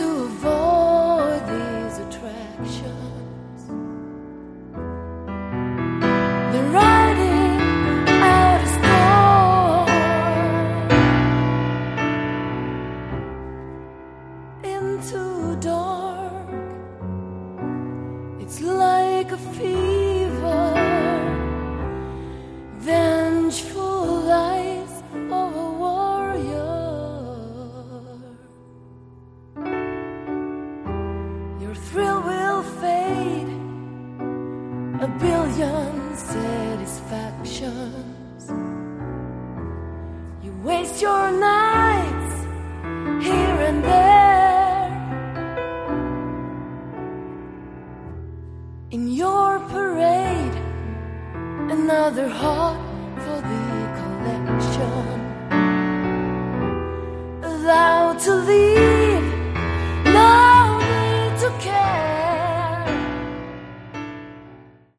Category: AOR
vocals
guitars
drums